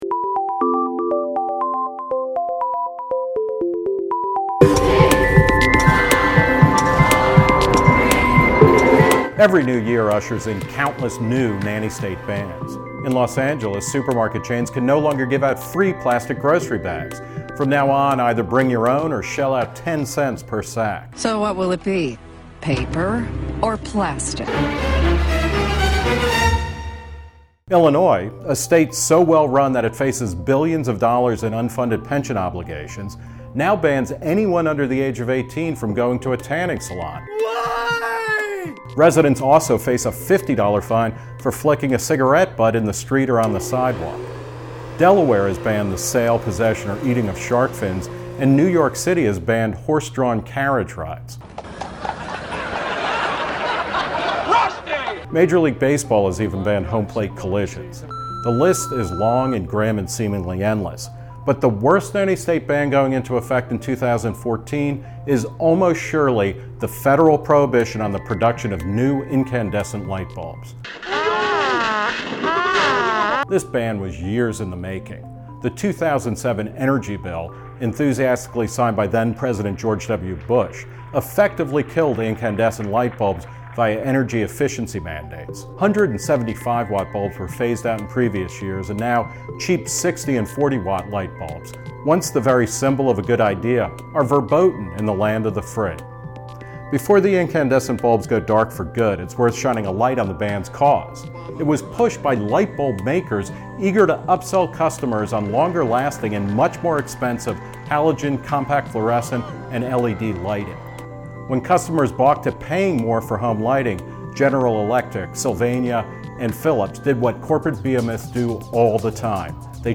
Written and narrated by Nick Gillespie.
Music by Chuzausen.